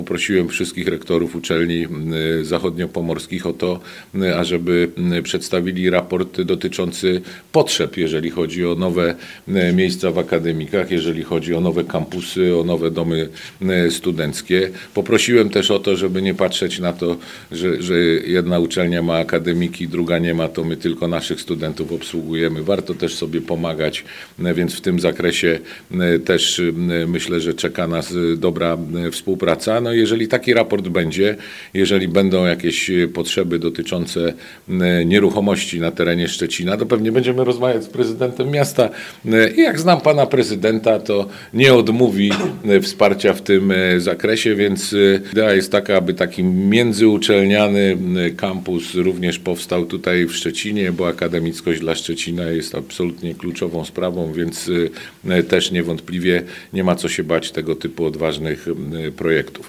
Minister Nauki i Szkolnictwa Wyższego, Dariusz Wieczorek, podczas dzisiejszego briefingu w Szczecinie, podkreślił konieczność modernizacji domów studenckich i zapowiedział plany dotyczące inwestycji w tym zakresie.